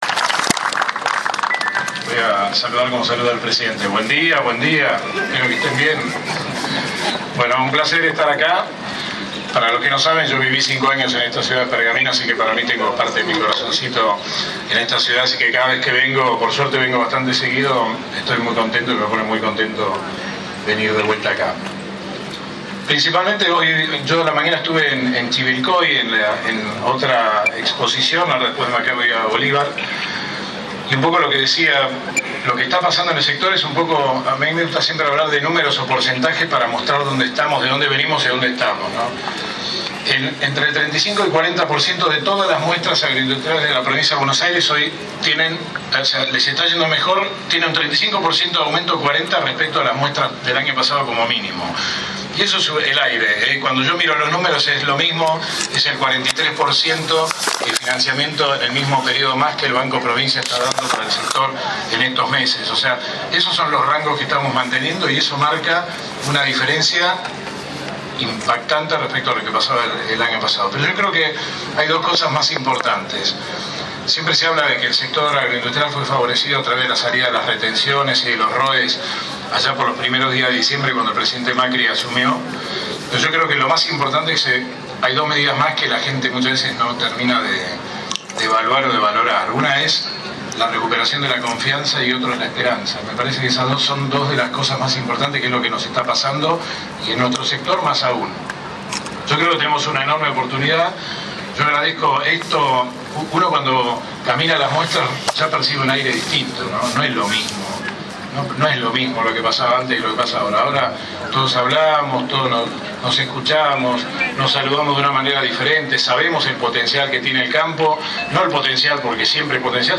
Ayer 10 de septiembre se realizó la apertura oficial en el patio principal de la expo.
Audio: Ministro de Agroindustria de la Prov. de Bs. As. Ing. Agrónomo Leonardo Sarquís.